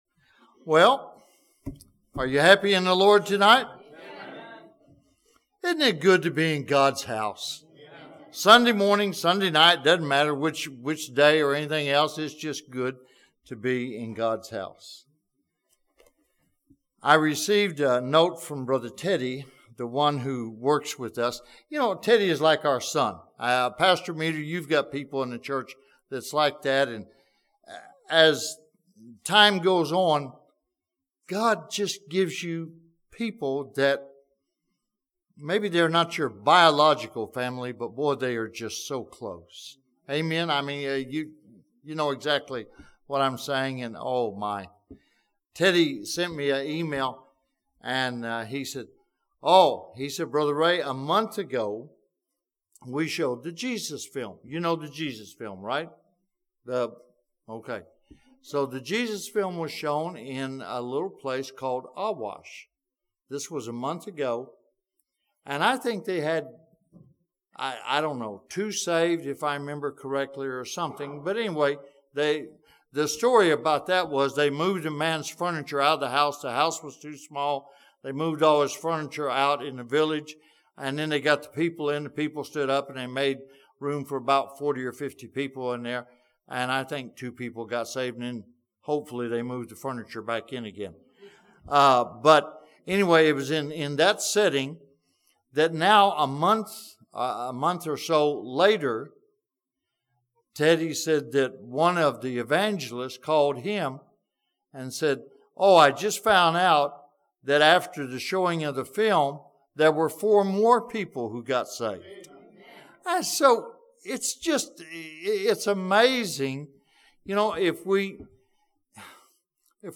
This sermon from 2 Peter chapter 3 that challenges believers to be about the business of preaching the Gospel.